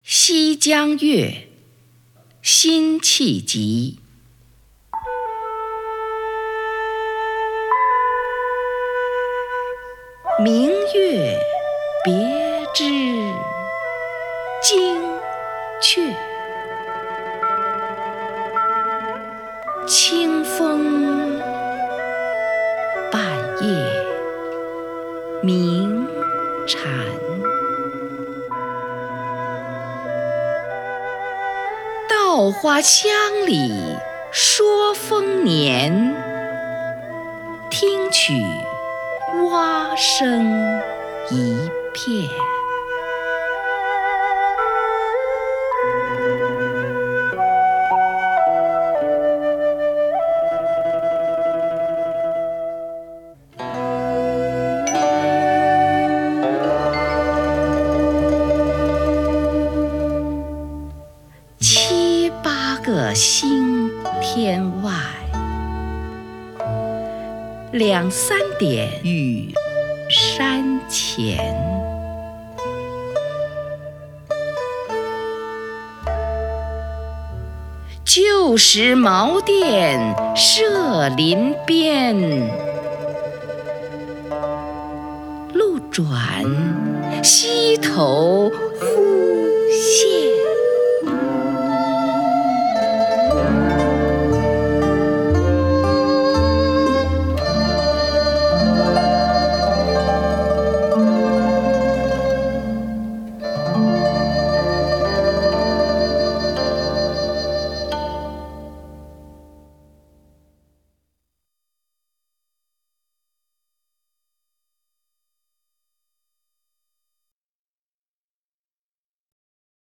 虹云朗诵：《西江月·夜行黄沙道中》(（南宋）辛弃疾)
名家朗诵欣赏 虹云 目录